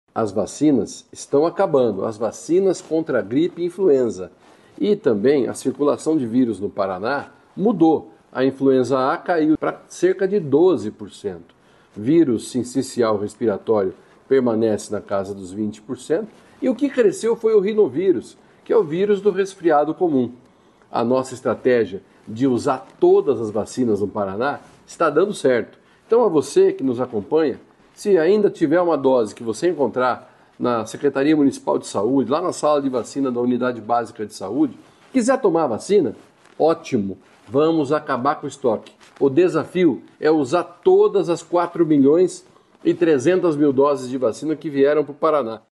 Sonora do secretário da Saúde, Beto Preto, sobre a queda na circulação do vírus Influenza no Paraná